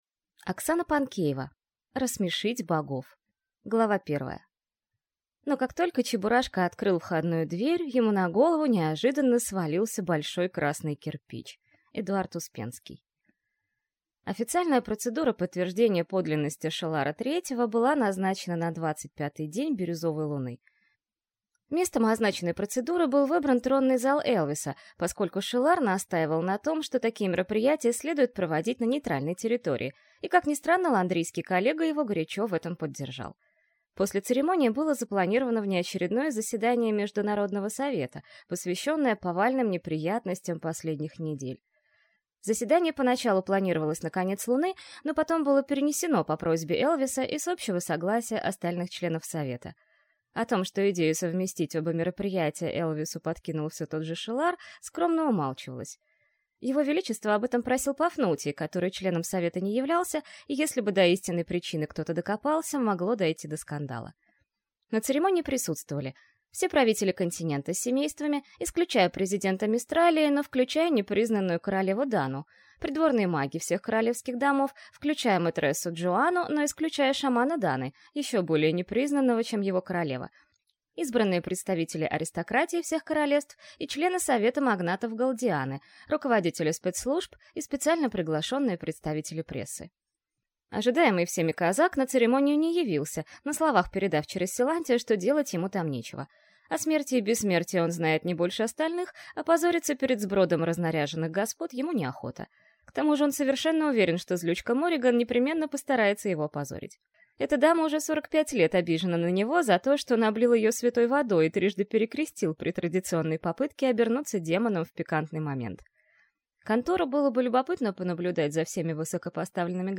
Аудиокнига Рассмешить богов | Библиотека аудиокниг